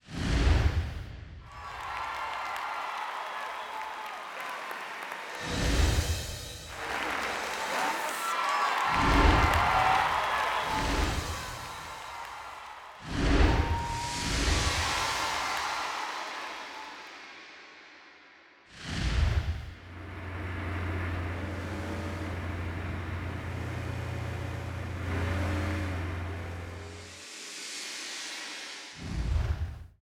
FORD_IMAGEAWARDS_FORD16IA_DDT30_ST SFX.wav